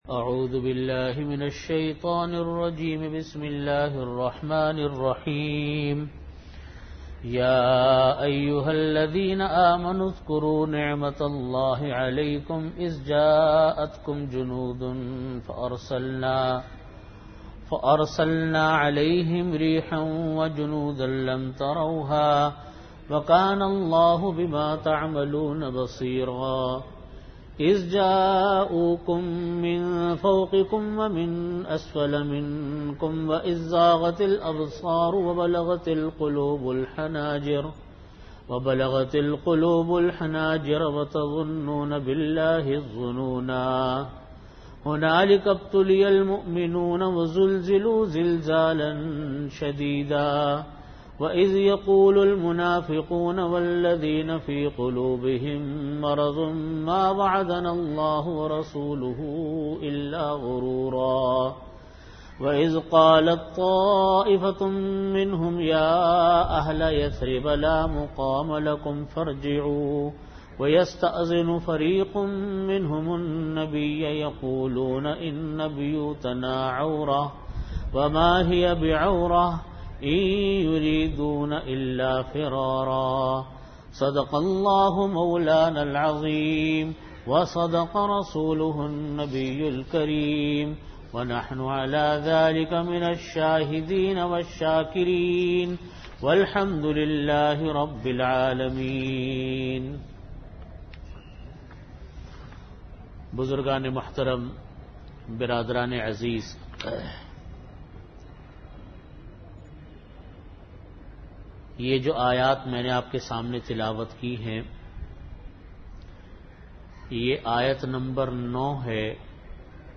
Audio Category: Tafseer
Venue: Jamia Masjid Bait-ul-Mukkaram, Karachi